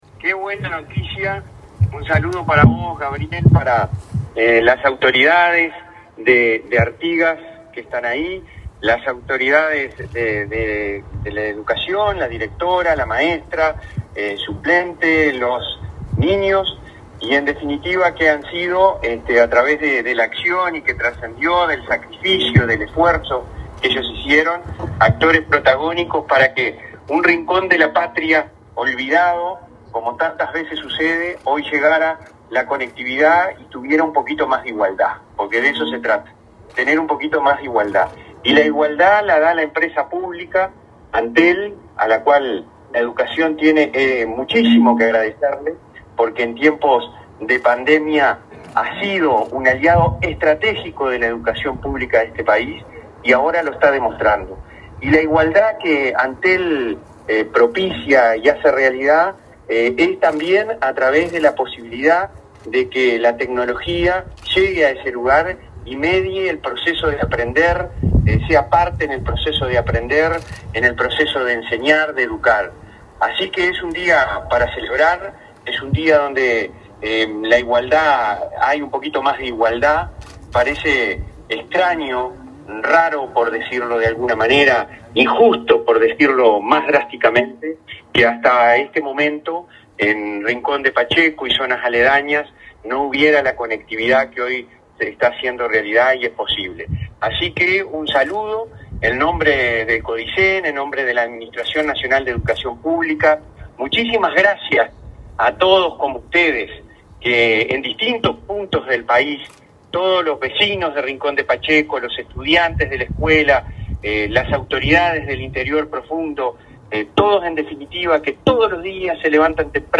Palabras del presidente de ANEP, Robert Silva 05/08/2021 Compartir Facebook X Copiar enlace WhatsApp LinkedIn Silva participó, mediante una llamada telefónica, en la inauguración de una radiobase en Rincón de Pacheco, Artigas, que optimizará la conectividad de internet de la escuela rural n.º 46.